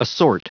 Prononciation du mot assort en anglais (fichier audio)
Prononciation du mot : assort